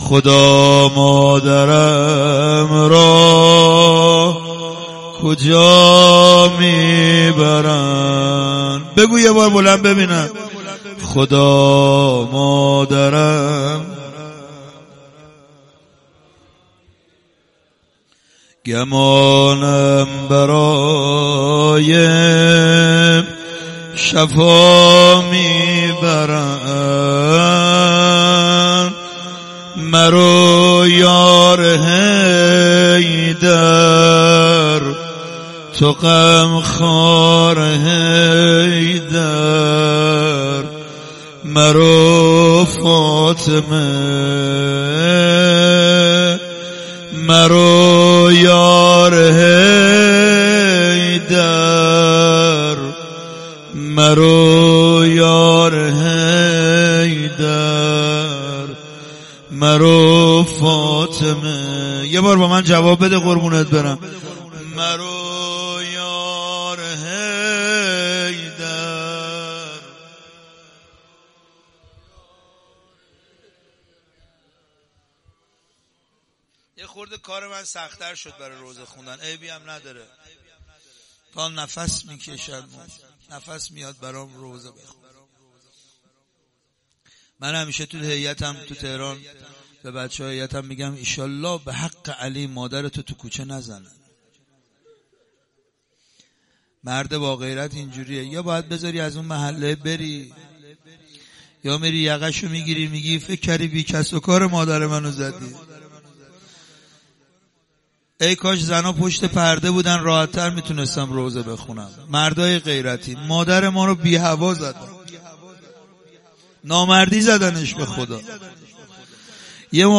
روضه.mp3